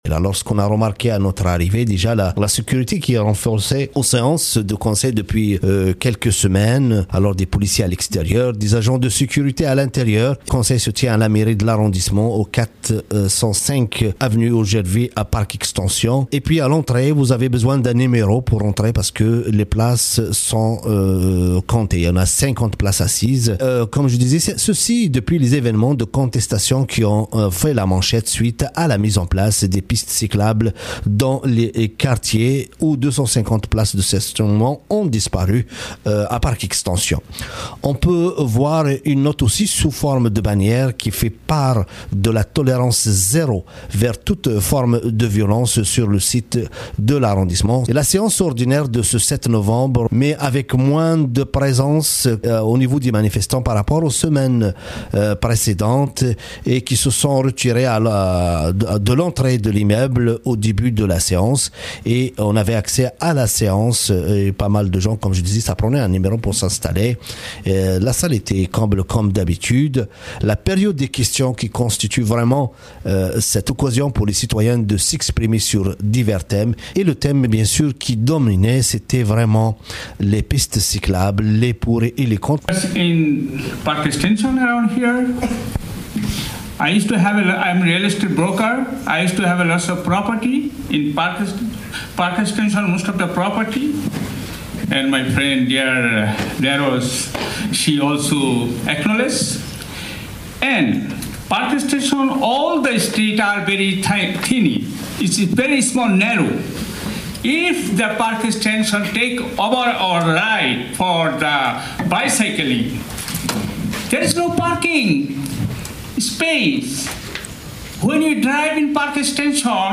Quelques échanges dans ce reportage